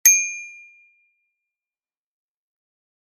ding.mp3